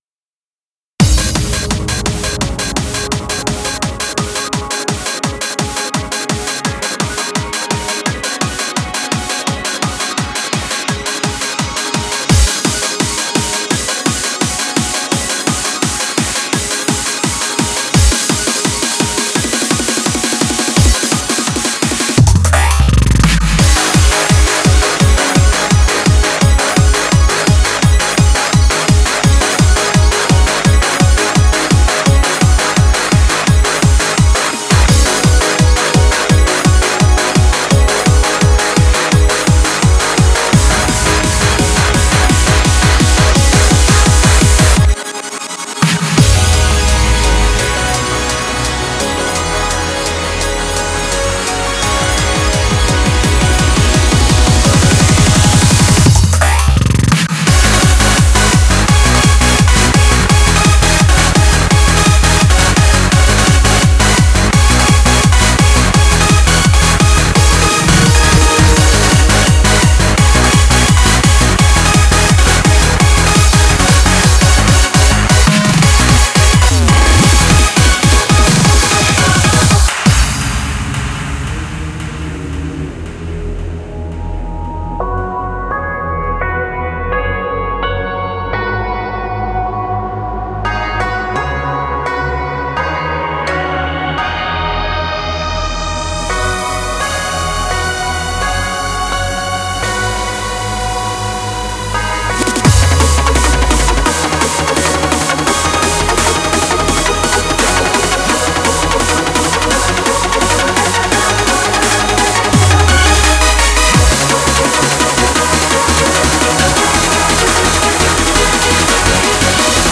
Audio QualityPerfect (Low Quality)
mashup